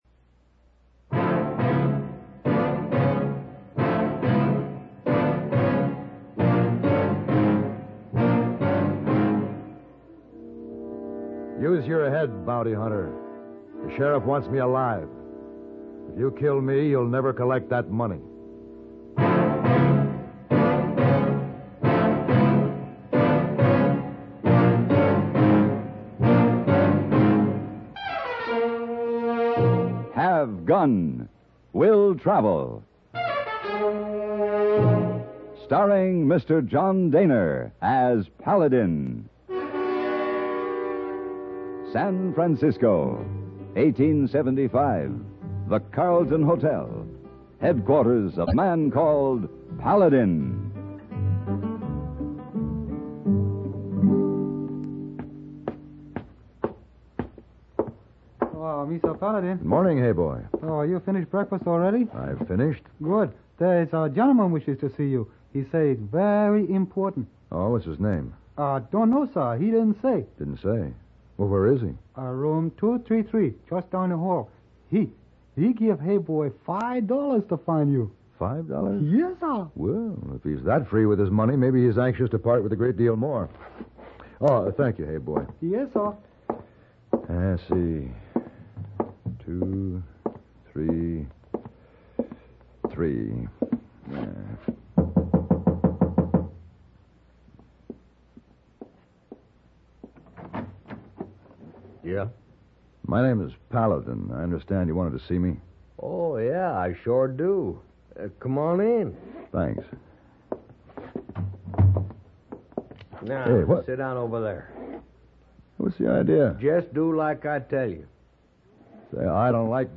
Will Travel Radio Program
Starring John Dehner